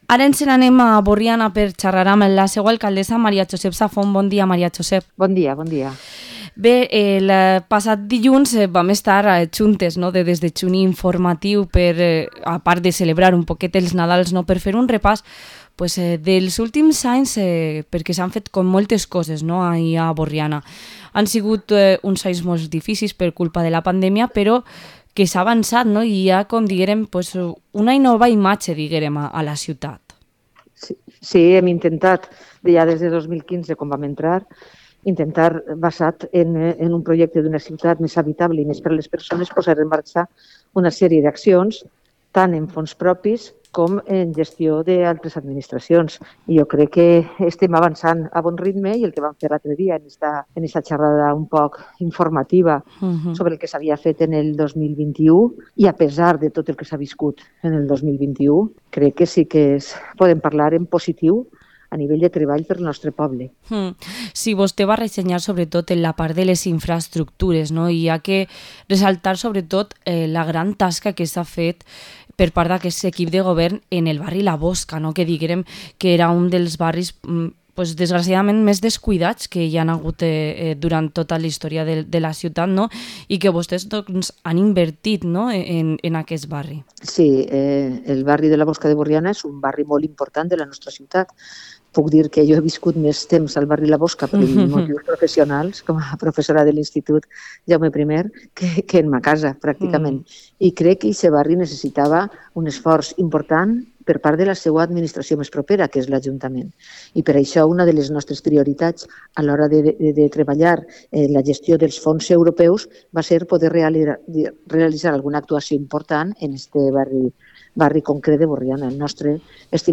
Entrevista a la alcaldesa de Burriana, Mª Josep Safont